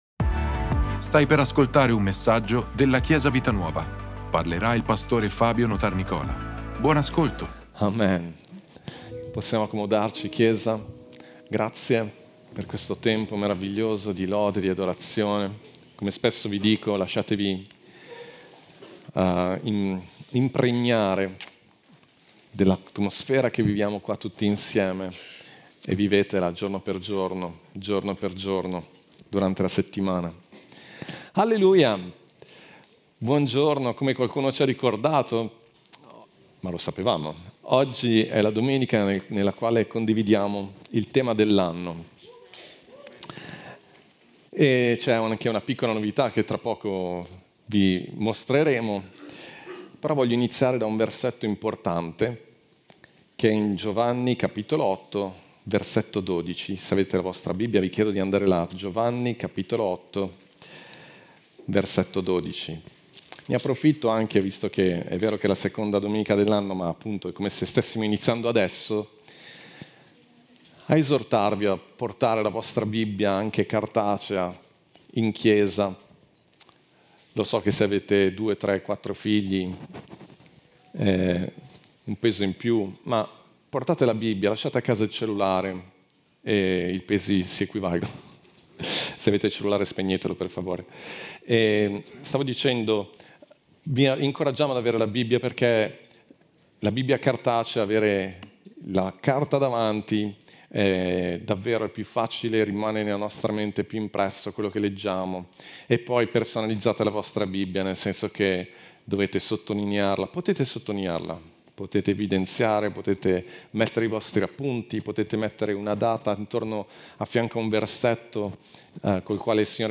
Ascolta la predicazione: 02/26_Noi accendiamo il mondo! - Chiesa Vita Nuova